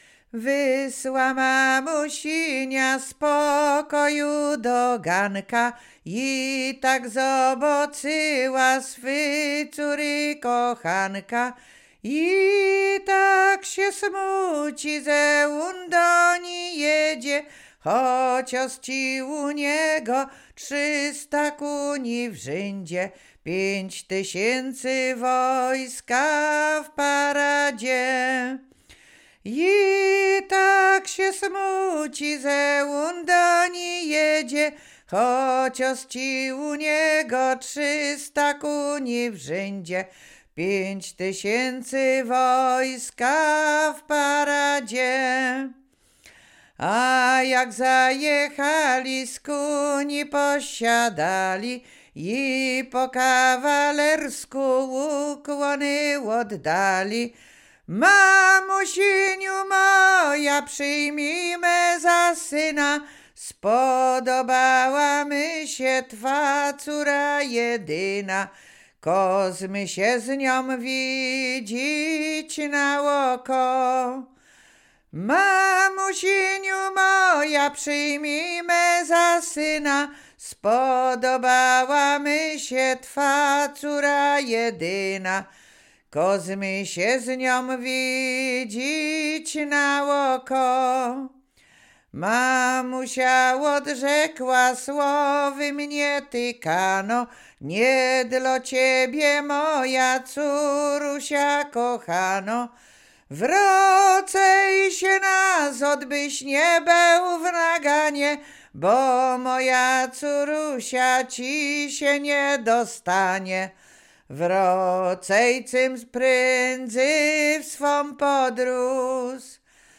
Łowickie
województwo łódzkie, powiat skierniewicki, gmina Lipce Reymontowskie, wieś Drzewce
liryczne miłosne